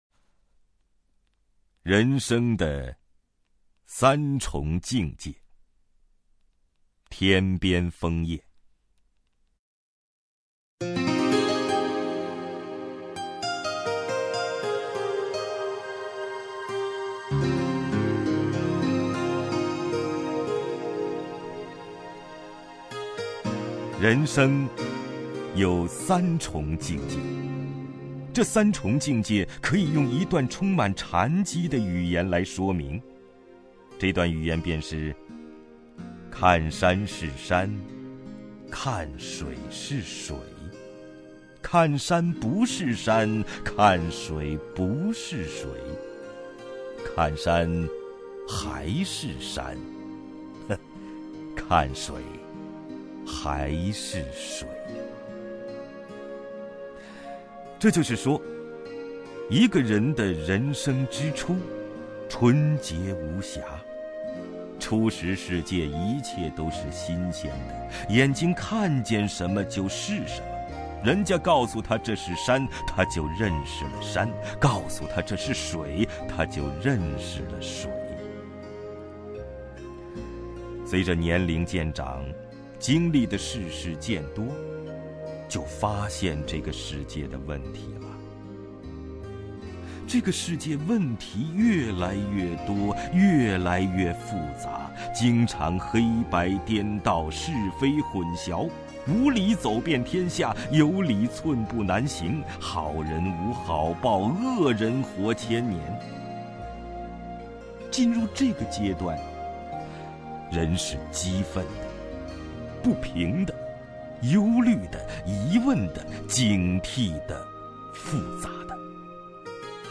王凯朗诵：《人生的三重境界》(天边风叶)
名家朗诵欣赏 王凯 目录